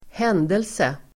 Uttal: [²h'en:delse]